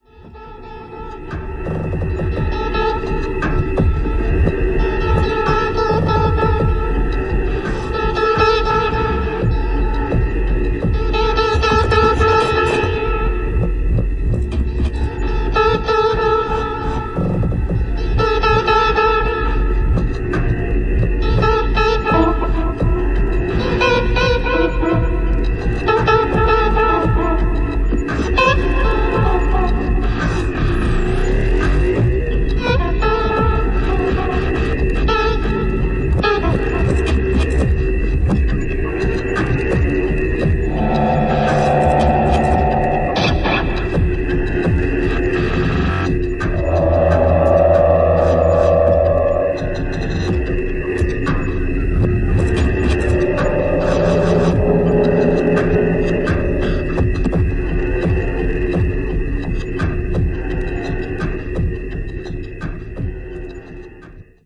synth, Key, Voice Fx.
Flute, Voice, Bass, Fx
Synth modular , Fx.